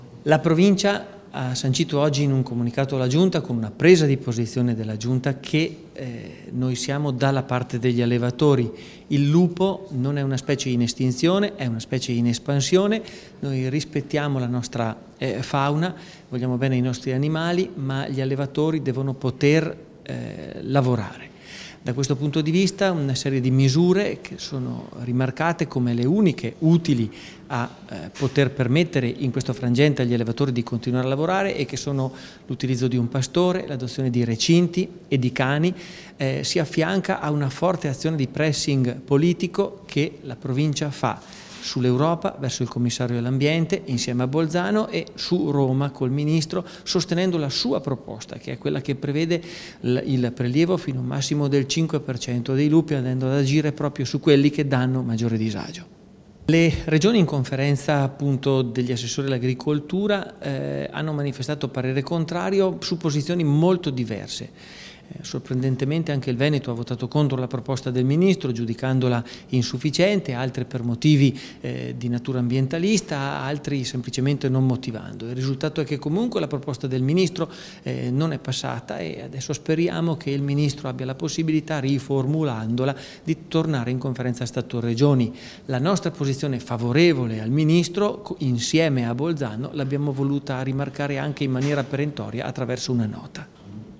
Così l’assessore Michele Dallapiccola, che oggi nel corso della conferenza stampa post Giunta, ha fatto il punto della situazione rispetto a quanto la Provincia sta facendo sul tema lupo in Trentino.